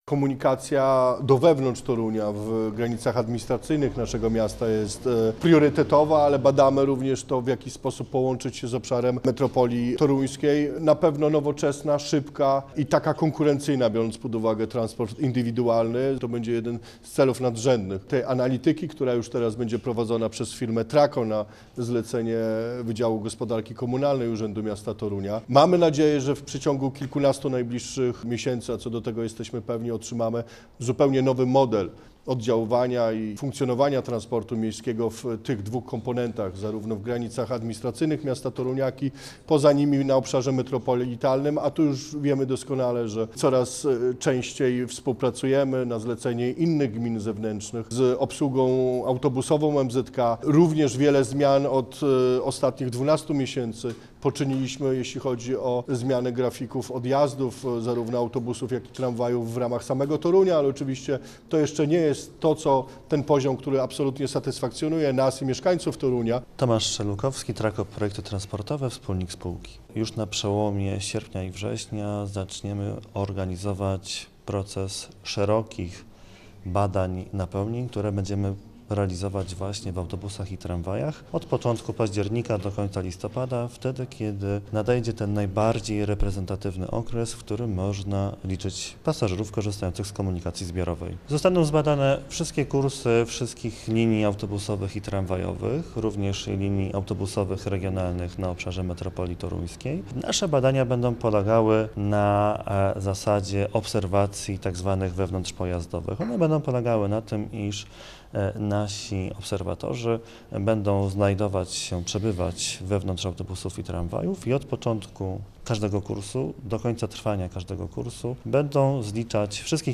Dziś podpisano umowę na opracowanie dokumentu "Docelowy układ sieci publicznego transportu zbiorowego Metropolii Toruńskiej". O szczegółach mówi prezydent Torunia Paweł Gulewski. 2025-06-16